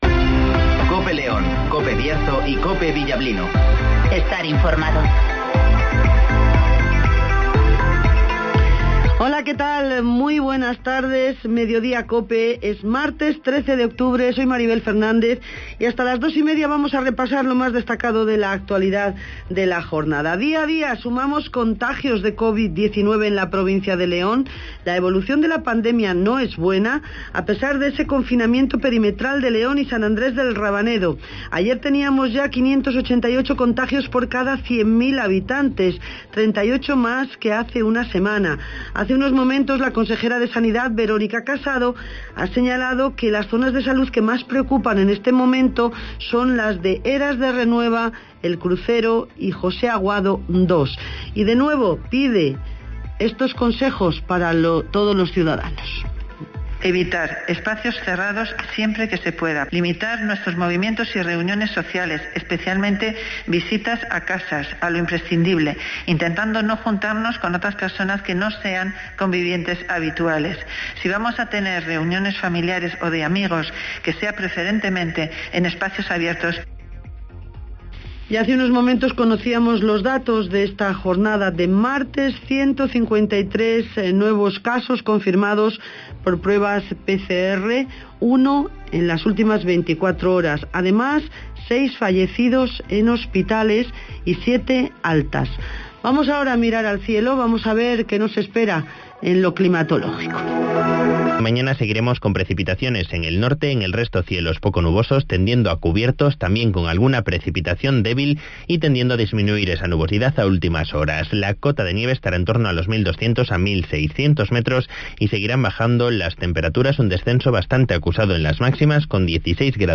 AUDIO: Repaso a la actualidad informativa de la provincia de León. Escucha aquí las noticias con las voces de los protagonistas.
Conocemos las noticias de las últimas horas del Bierzo y León, con las voces de los protagonistas.